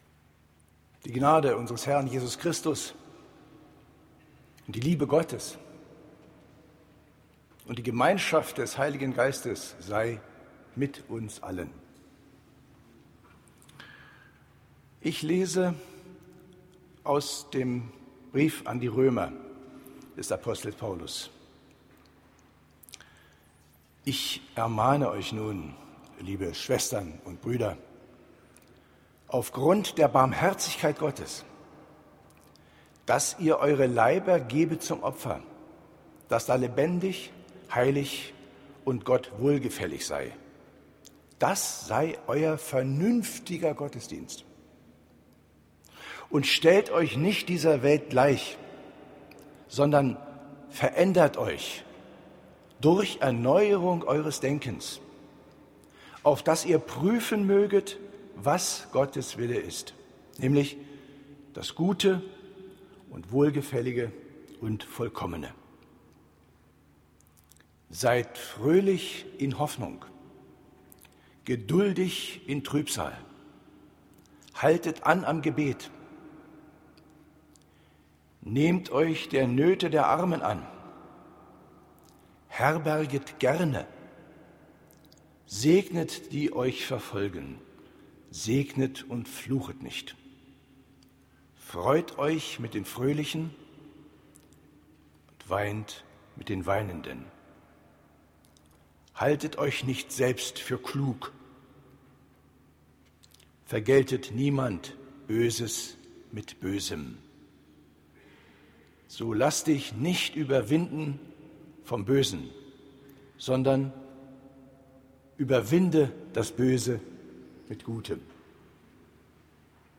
Predigten 2019